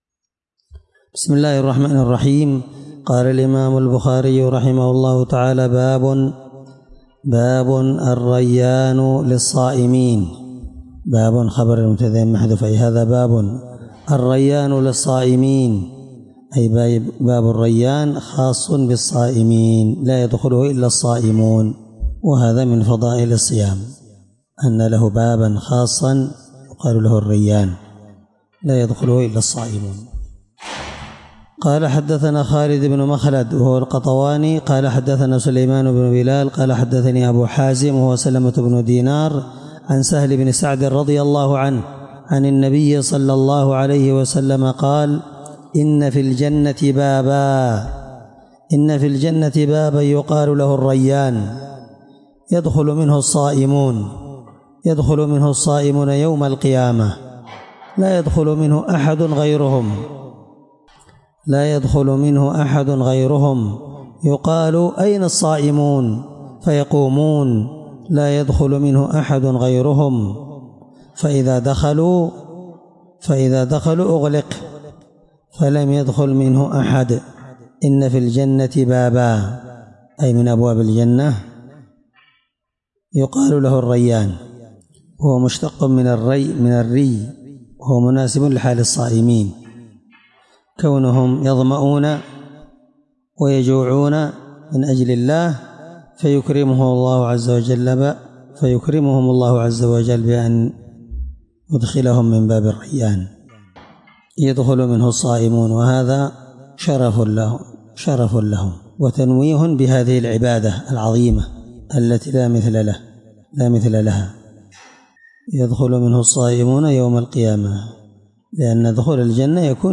الدرس 4من شرح كتاب الصوم حديث رقم(1896 )من صحيح البخاري